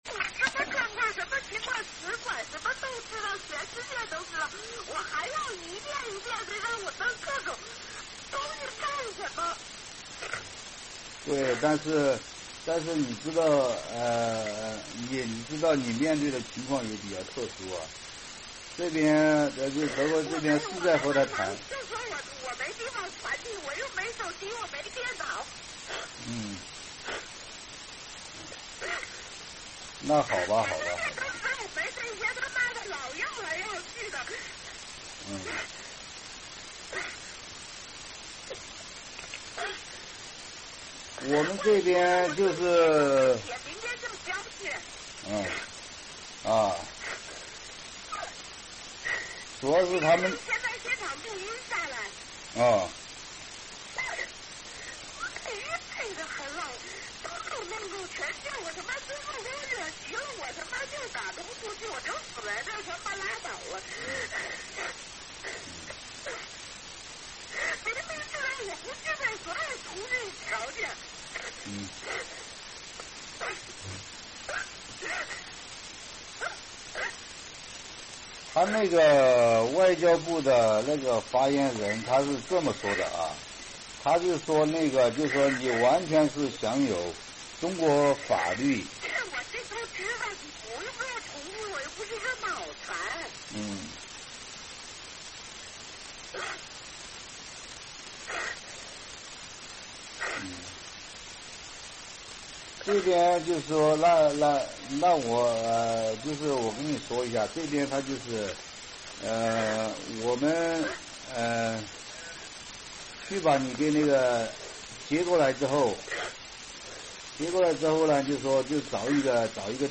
在一阵哭声后，录音中可以隐约听到一个哽咽的声音：“这是在逼着我做那些我做不到的事情……”